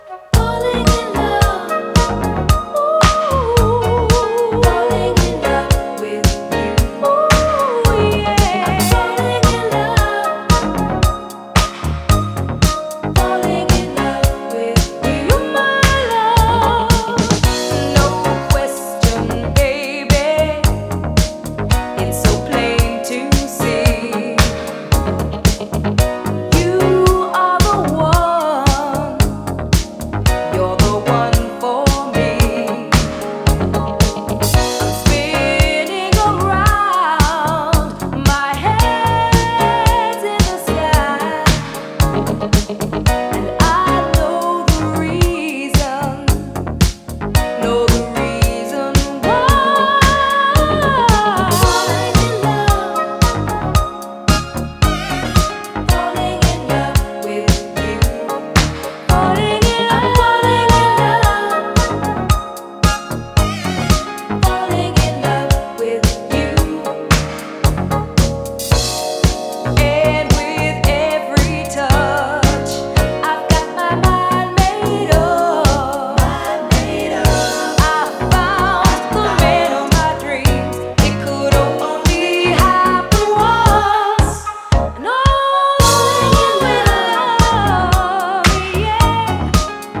ジャンル(スタイル) DISCO / SOUL / FUNK